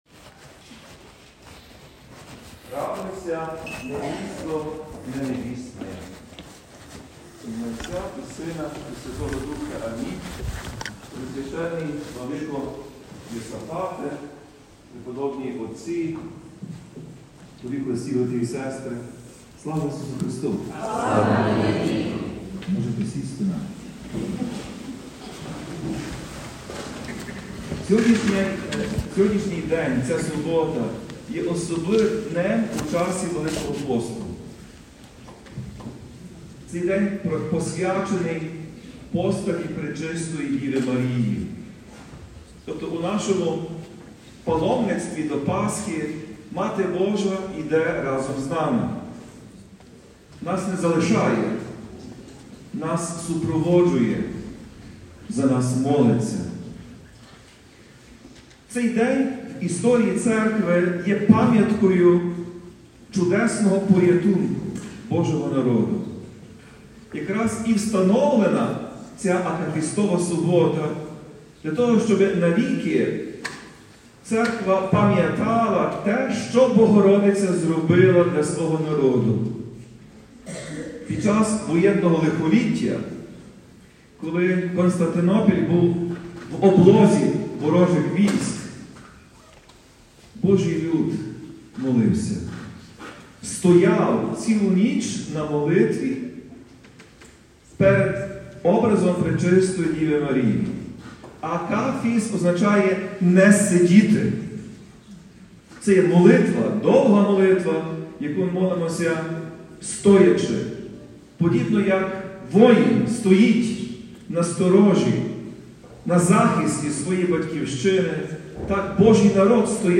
Про це 5 квітня, в Акафістову суботу, під час Літургії у храмі Святого Василія Великого отців василіан у Луцьку сказав Отець і Глава УГКЦ Блаженніший Святослав.